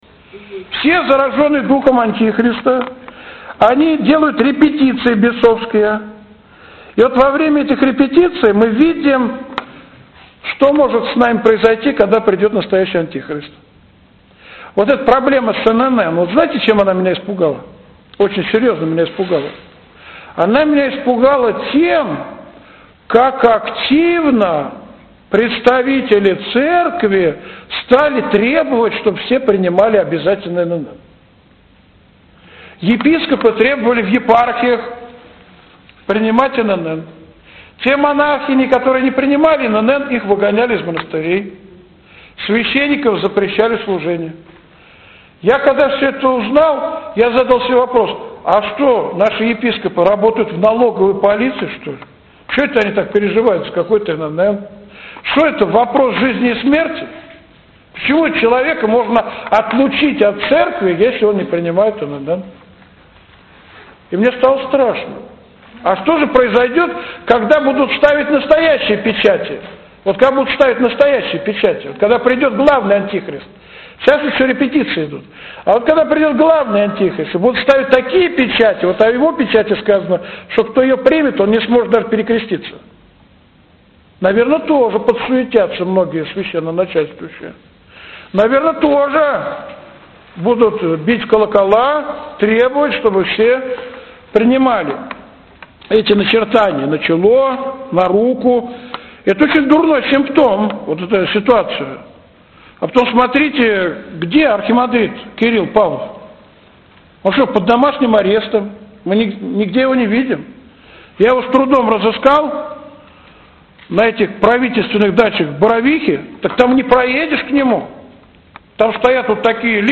Беседа